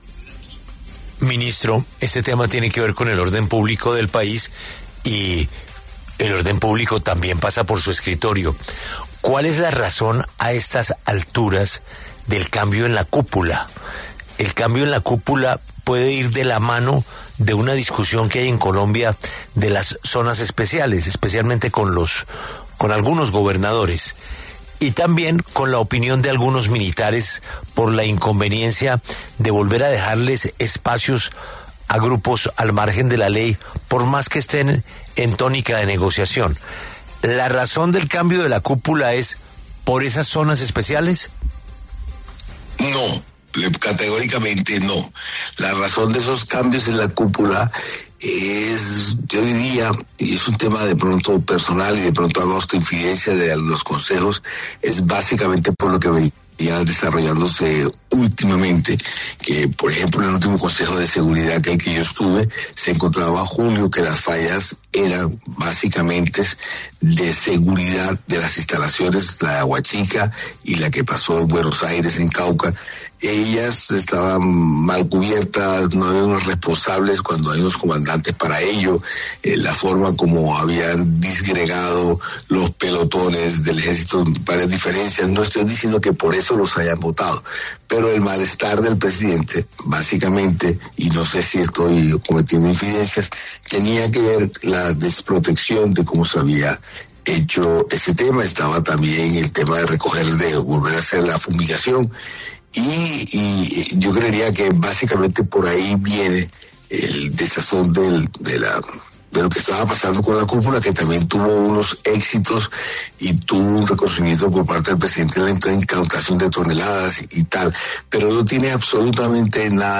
El presidente Petro sorprendió este fin de semana con un remezón en la cúpula militar del país y, en diálogo con La W, el ministro del Interior, Armando Benedetti, se pronunció al respecto revelando lo que, para él, sería la razón de esos cambios en las instituciones militares de Colombia.